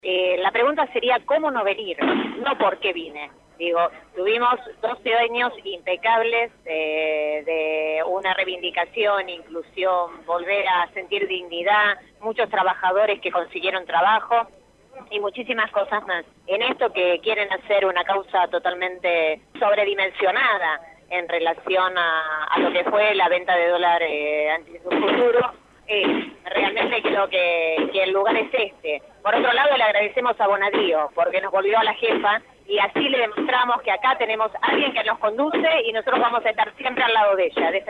(13/4/2016) Citada a indagatoria por el juez Claudio Bonadio, en la causa de dólar futuro, esta mañana Cristina Fernández de Kirchner presentó un escrito en los Tribunales de Comodoro Py, mientras que en las afueras de la sede judicial una multitud proveniente de distintos puntos del país se congregó para brindar su apoyo a la ex mandataria.